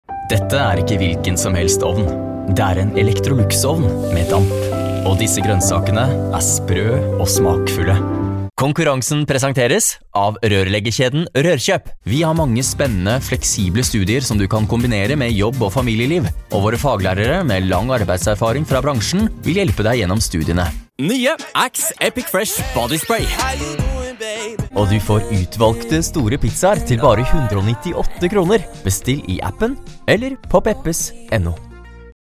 Freundlich
Cool
Knackig